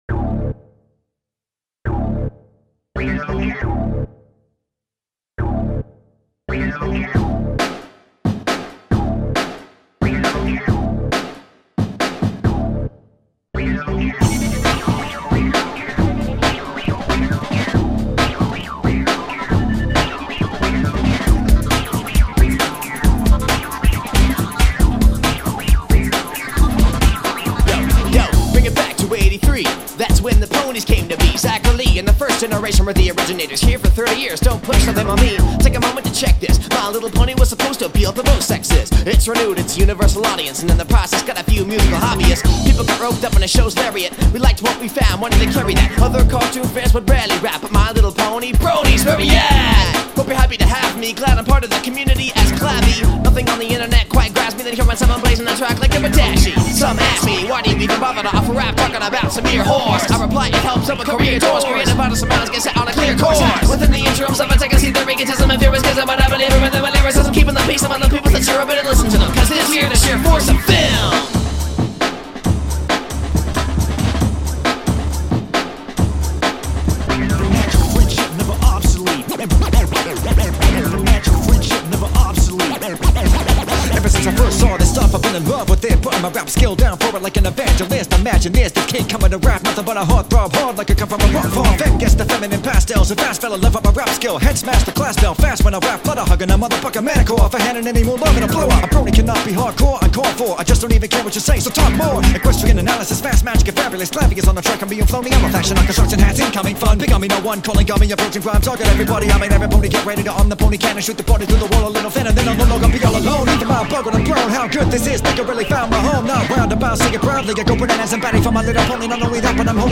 Scratching